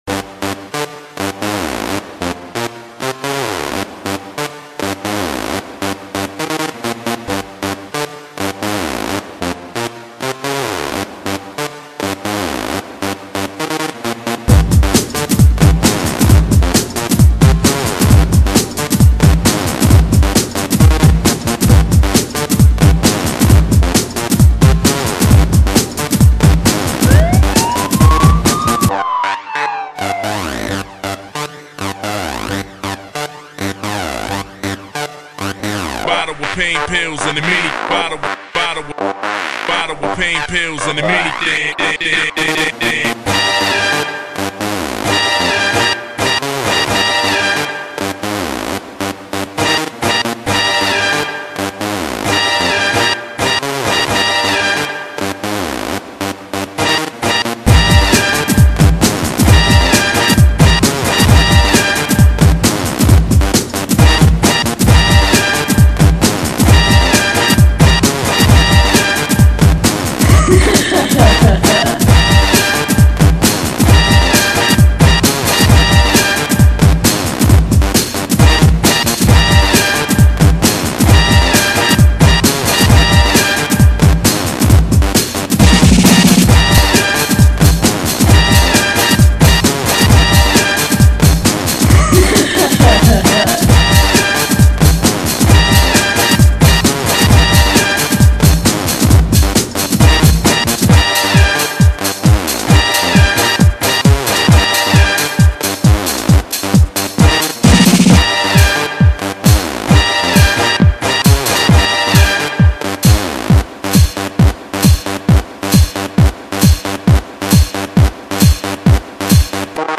Быстрый трек с соответствующей атмосферой big beat